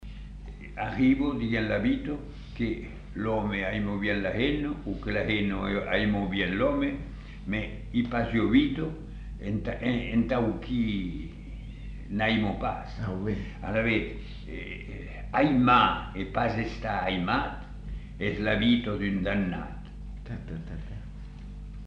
Lieu : Masseube
Effectif : 1
Type de voix : voix d'homme
Production du son : récité
Classification : proverbe-dicton